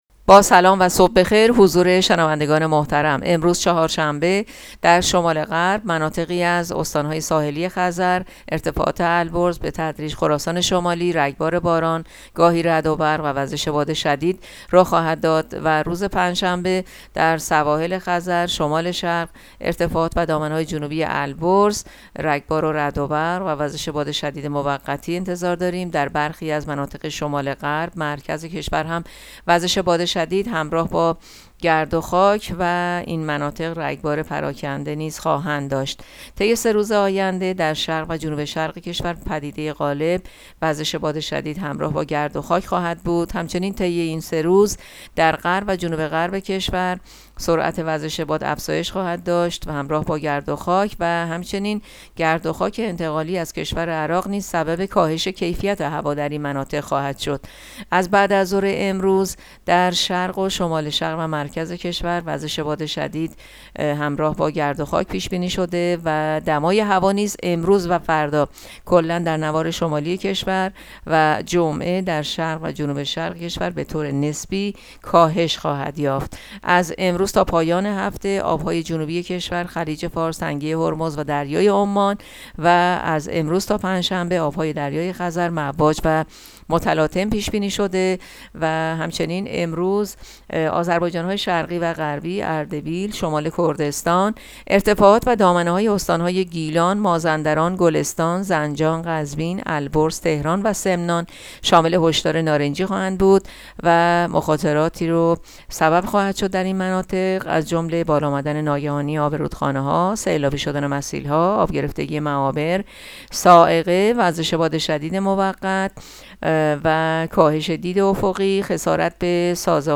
گزارش آخرین وضعیت جوی کشور را از رادیو اینترنتی پایگاه خبری وزارت راه و شهرسازی بشنوید.